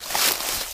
STEPS Bush, Walk 05.wav